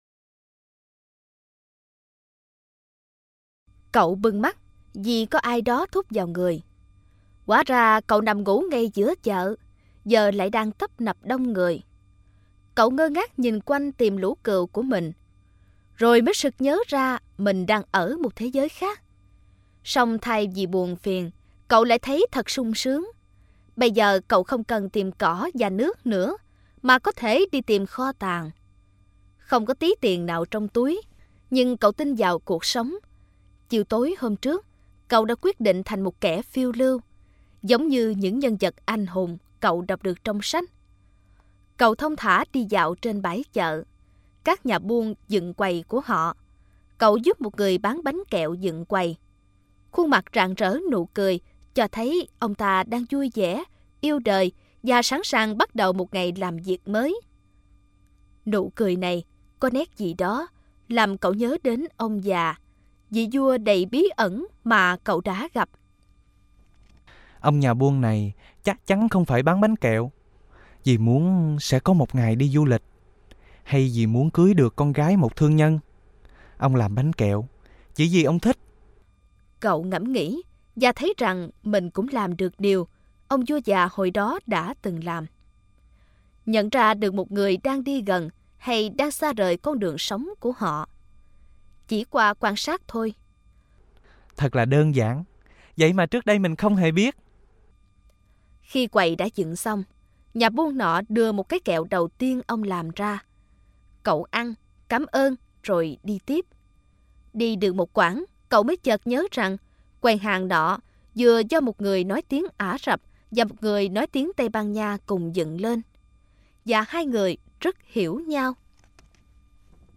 Sách nói | Nhà giả kim 6